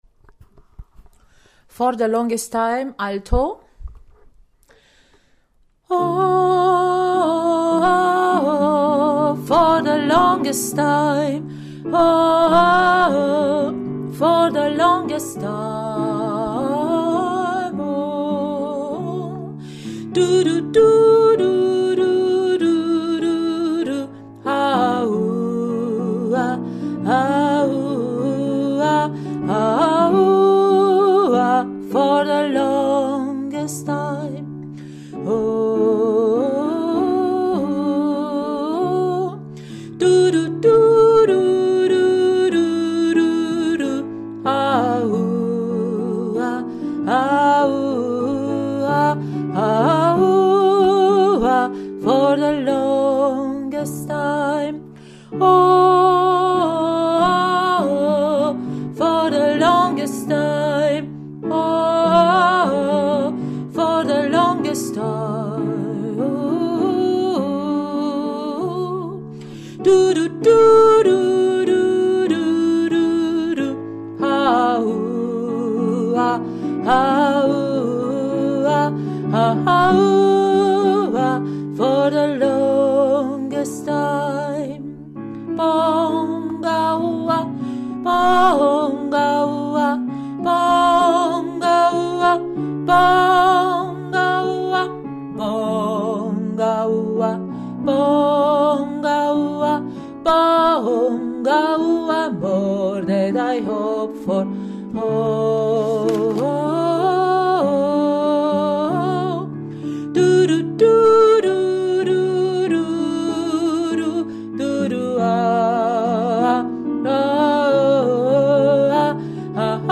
For the longest time – Alto